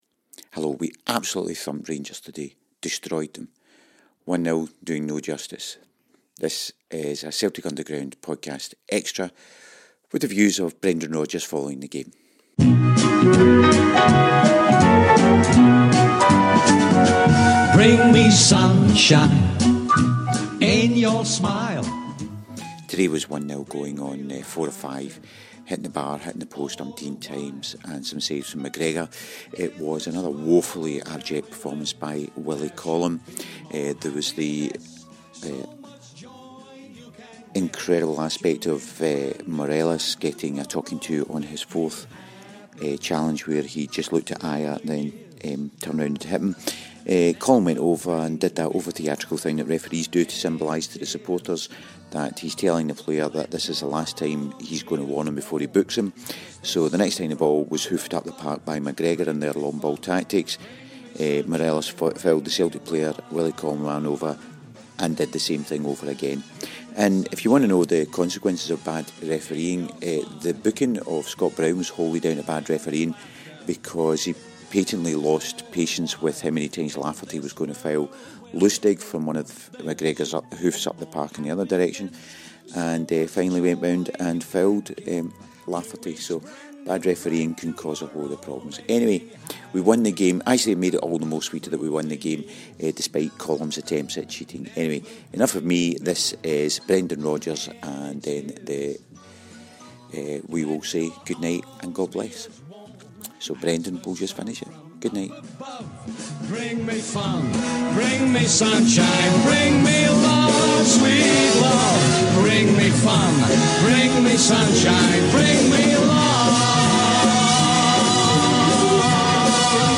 After the game, Brendan spoke with the press and also gave his final word on the Dembele debacle.